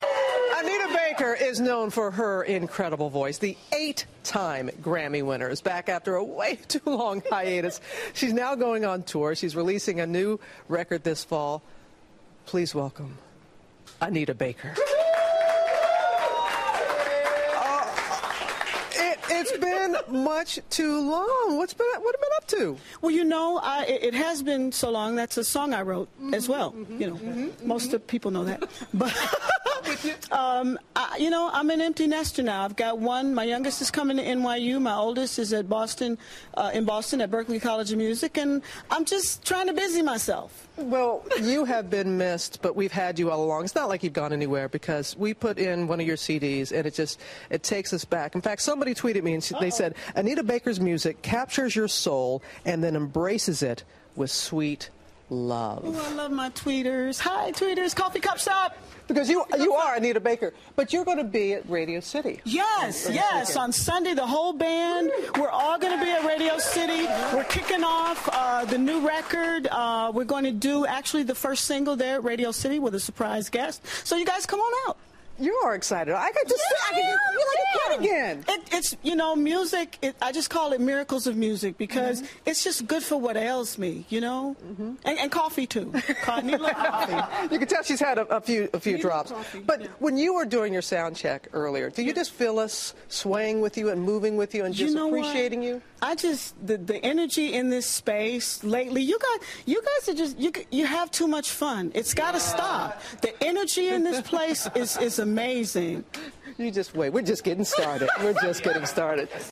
访谈录 2012-05-06&05-08 一代歌姬安妮塔.贝克专访 听力文件下载—在线英语听力室